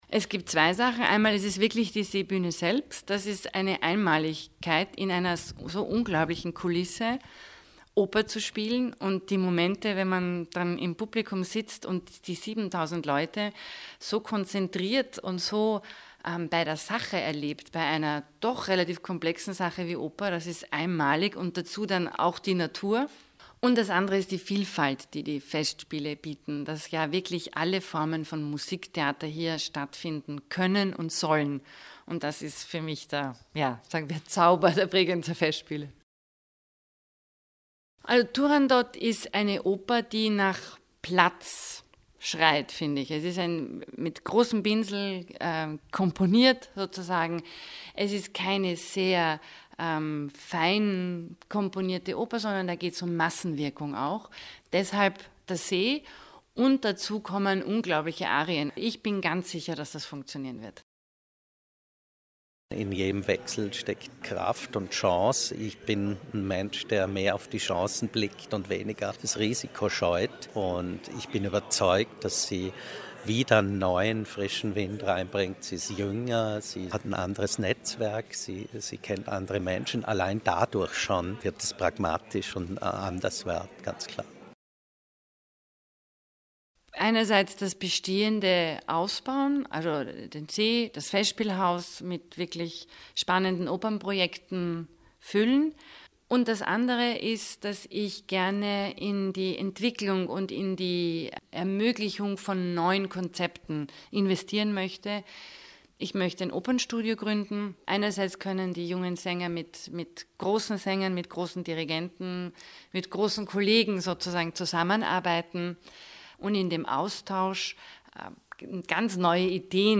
O-Ton Pressekonferenz "Bregenzer Festspiele ab 2015" - feature